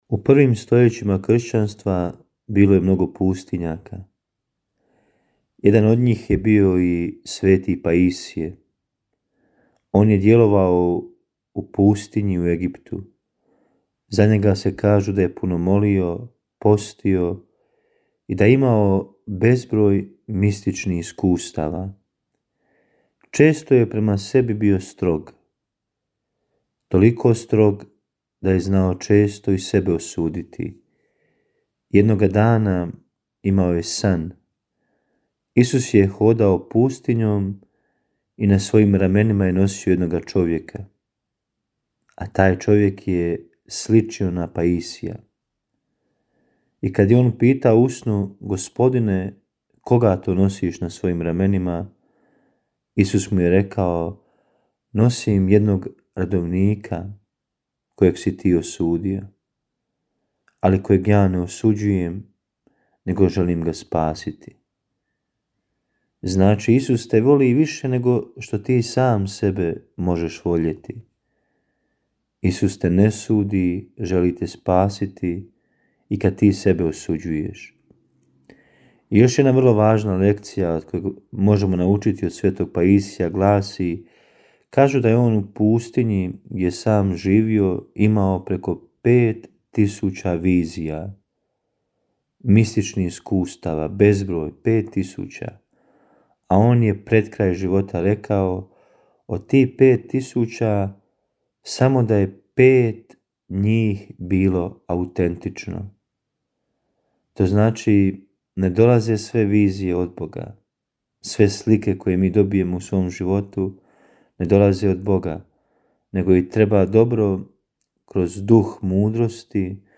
Emisije priređuju svećenici i časne sestre u tjednim ciklusima.